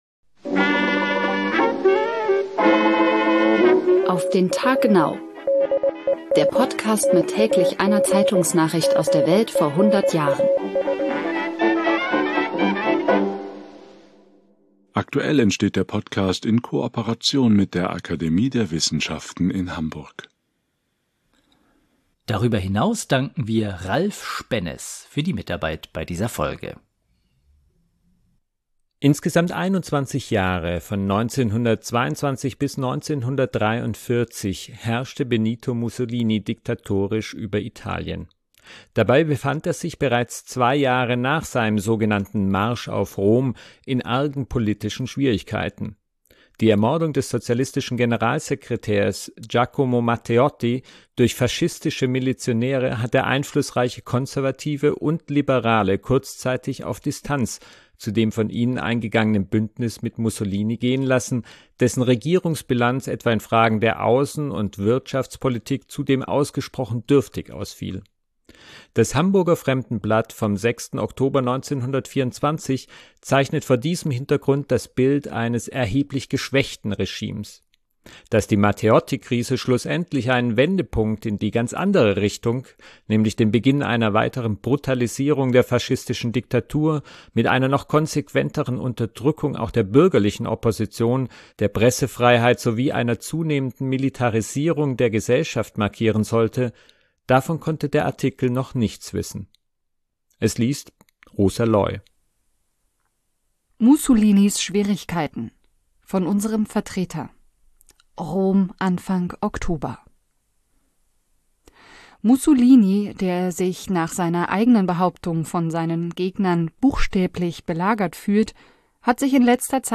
Der Podcast mit täglich einer Zeitungsnachricht aus der Welt vor hundert Jahren